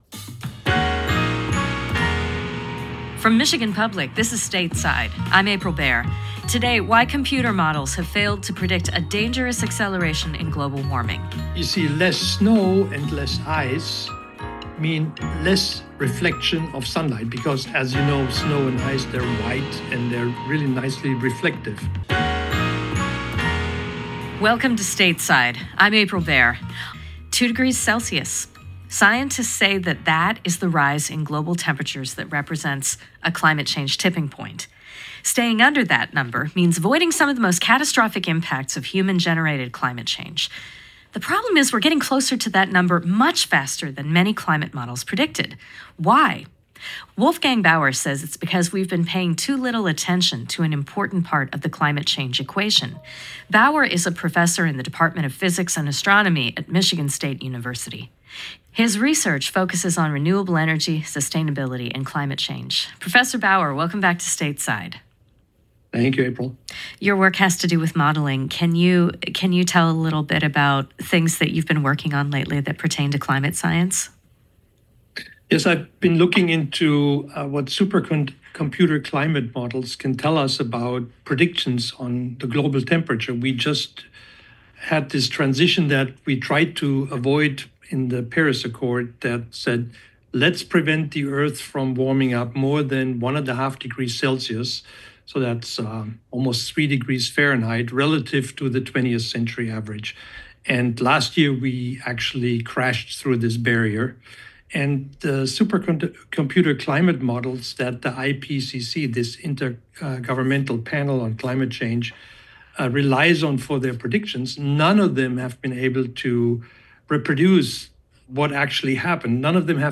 April 2025 Stateside radio program Interview on global warming. Ask the expert: Why is the Earth heating up faster than expected?